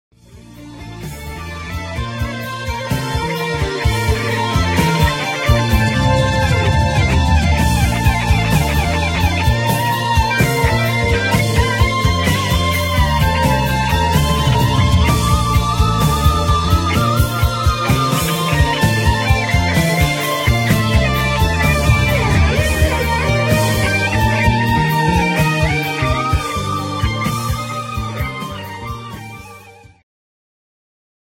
Segment Progressive
Rock
Guitar Future
Synthesizer Future
Symphonic
Vocal Future